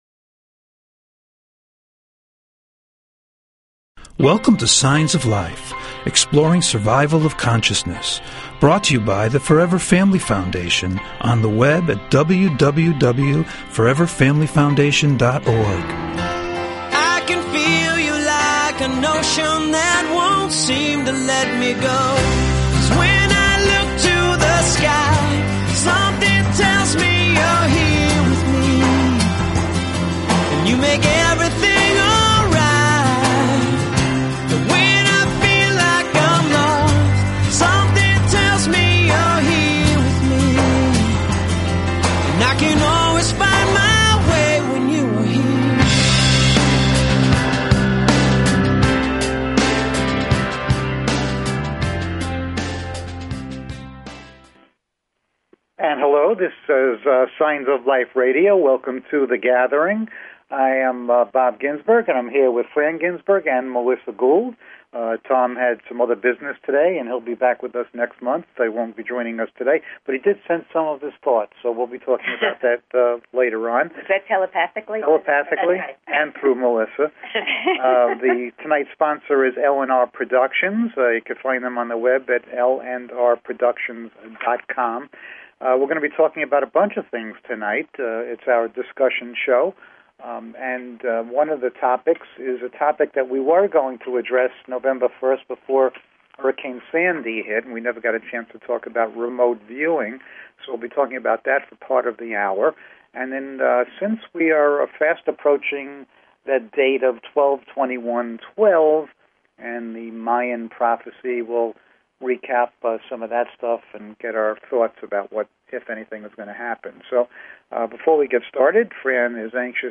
Talk Show Episode, Audio Podcast, Signs_of_Life and Courtesy of BBS Radio on , show guests , about , categorized as
SHORT DESCRIPTION - Discussion Show
Call In or just listen to top Scientists, Mediums, and Researchers discuss their personal work in the field and answer your most perplexing questions.